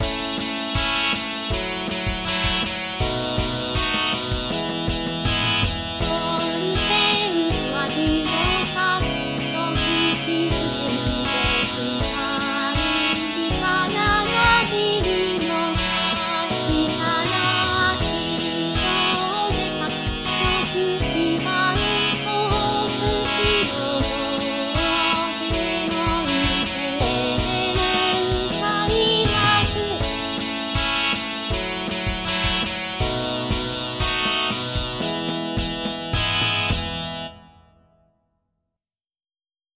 自動で作曲し、伴奏つきの
合成音声で歌います。